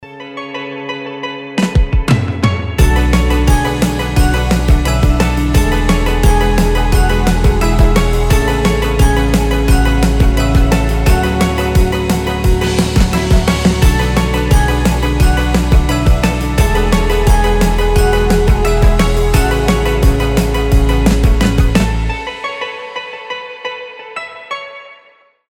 • Качество: 320, Stereo
мелодичные
без слов
Cover
звонкие
биты
Мелодичный инструментальный кавер на песню